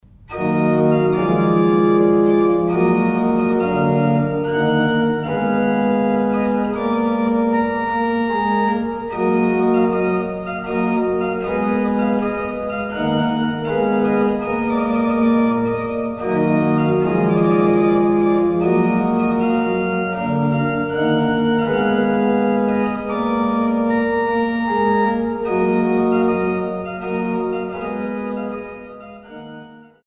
an der Renkewitz-Orgel auf Schloss Augustusburg